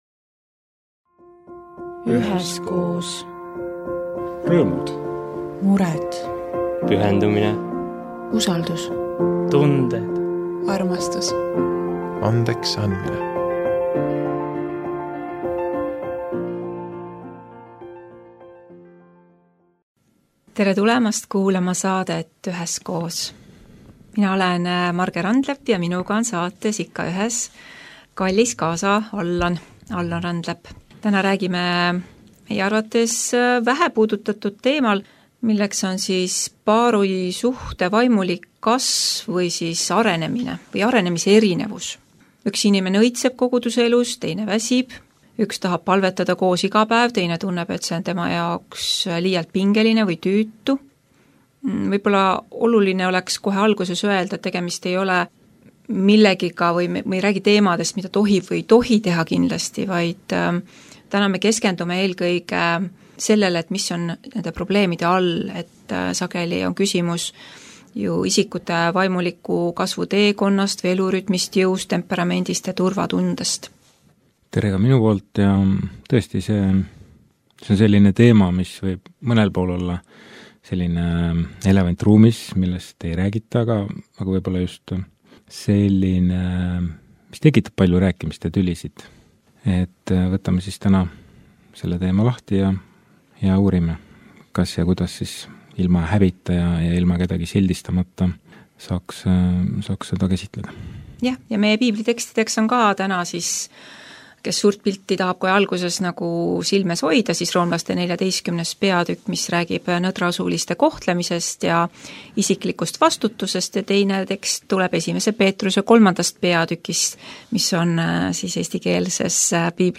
Saade oli Pereraadio eetris 26.01.2026.